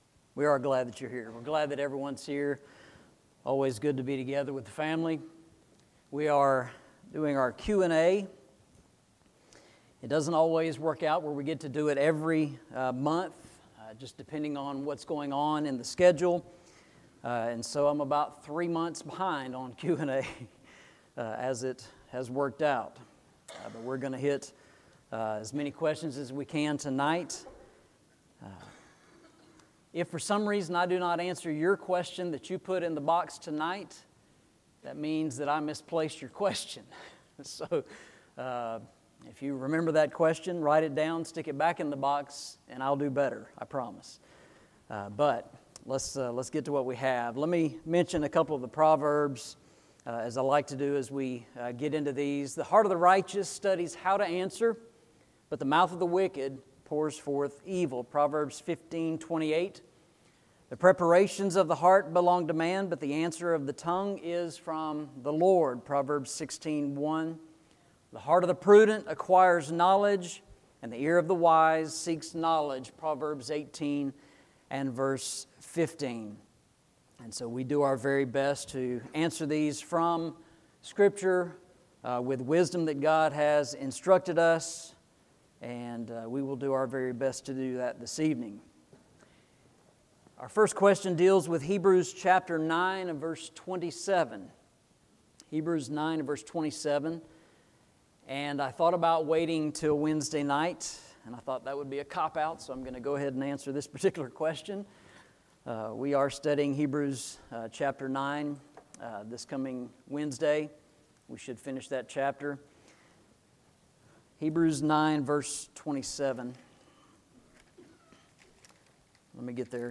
Series: Eastside Sermons Passage: I Peter 3:15 Service Type: Sunday Evening « THE CHALLENGE OF CHRIST Can You Recommend Your Religion?